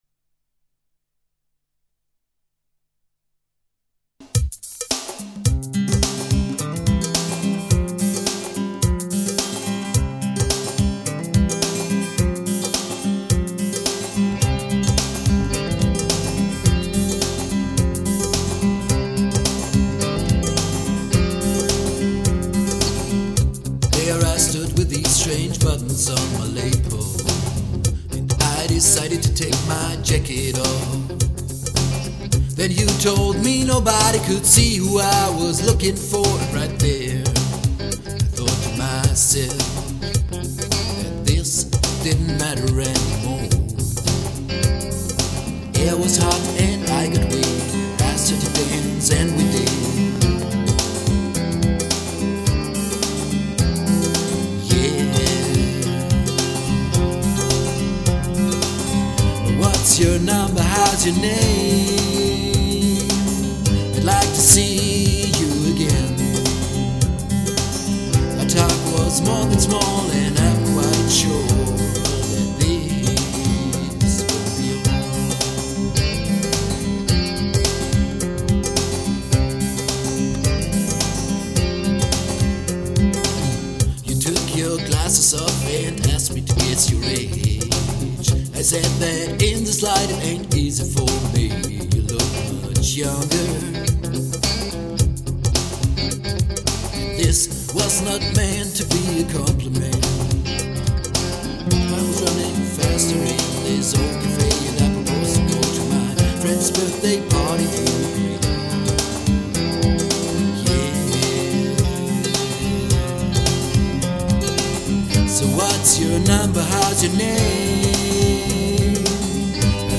Telemaster
bass
drums: QY-20